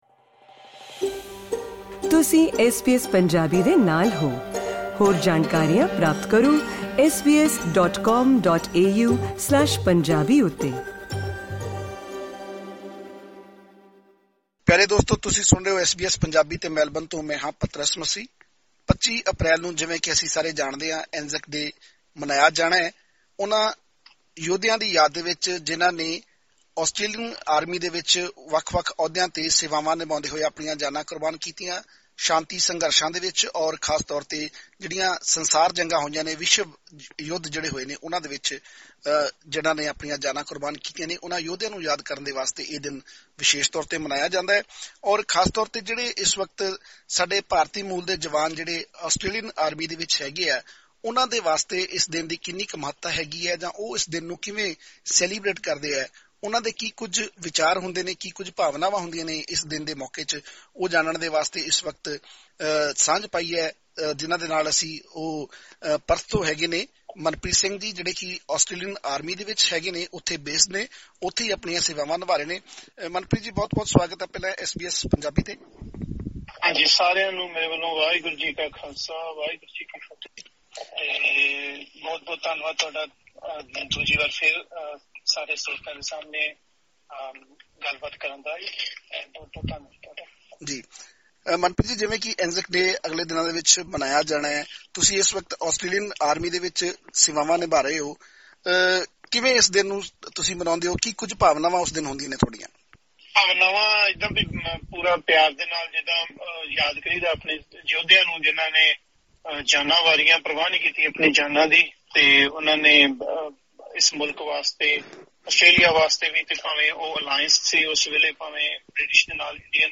Listen to this interview in Punjabi by clicking on audio icon...